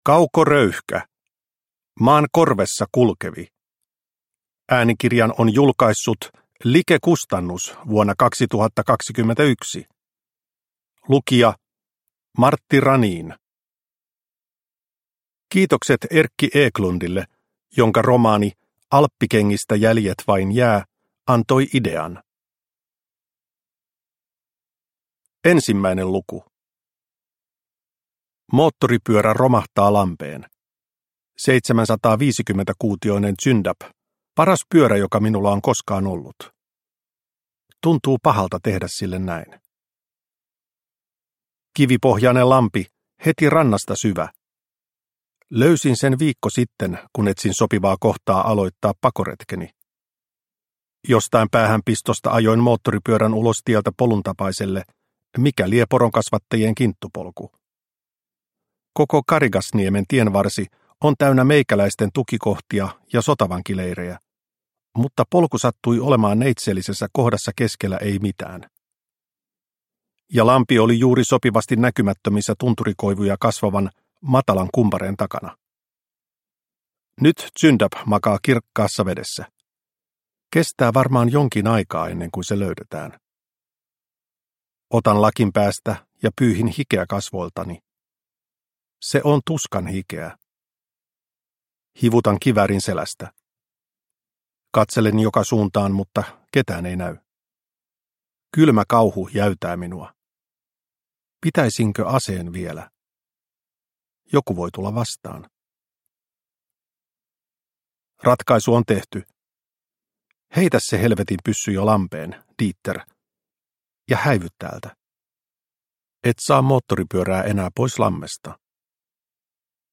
Maan korvessa kulkevi – Ljudbok – Laddas ner